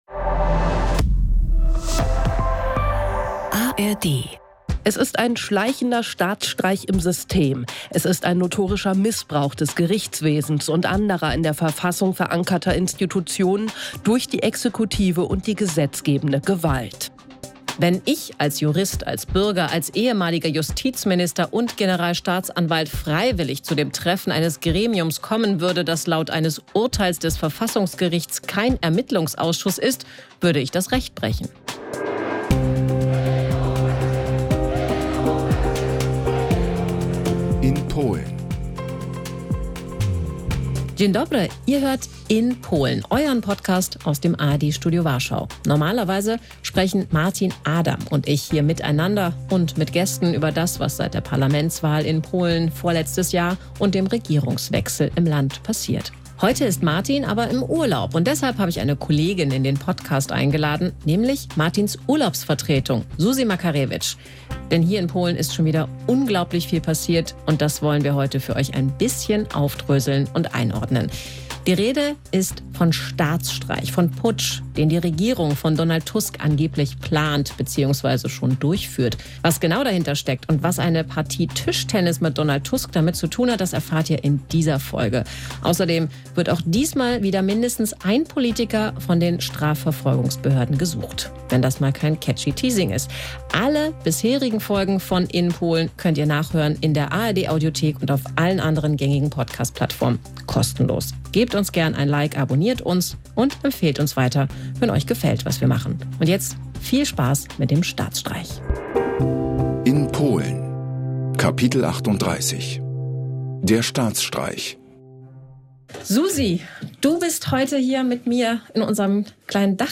der Podcast aus dem ARD-Studio in Warschau